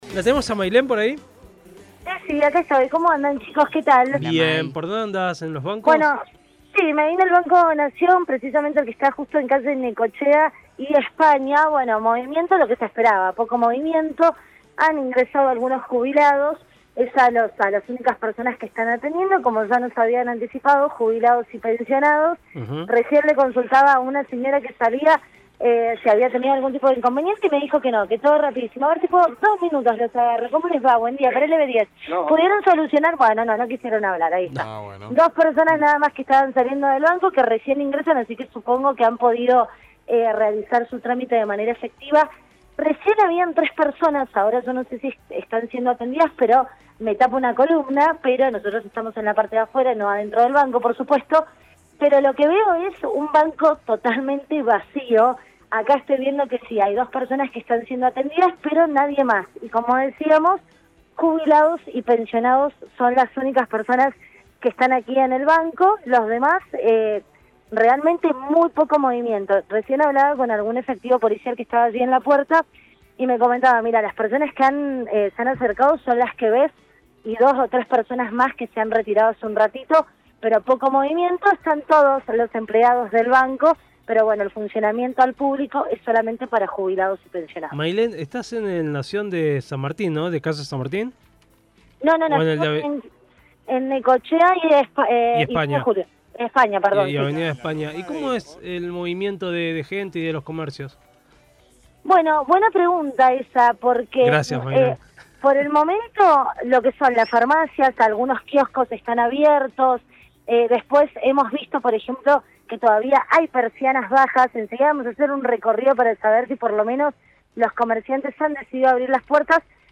Móvil de LVDiez desde Banco Nación, Necochea y 9 de Julio, Cdad- Paro: Sólo pagan jubilaciones